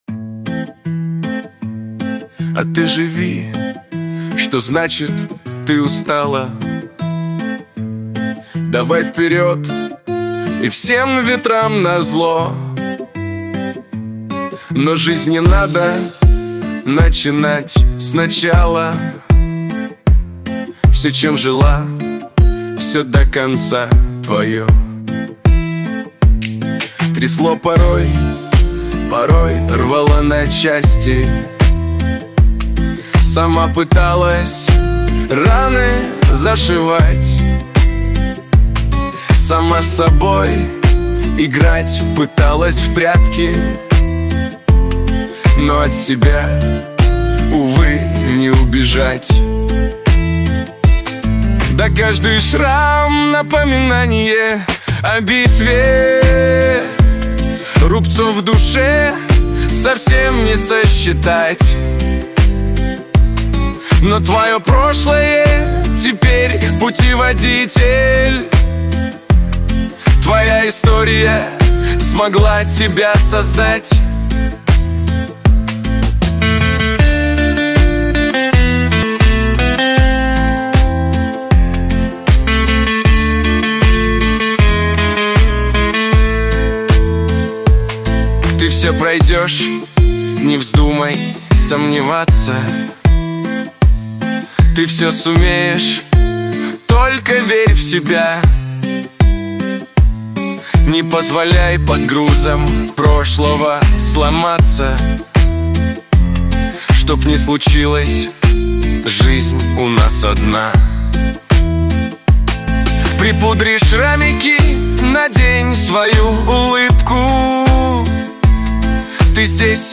Новая песня создана с помощью ИИ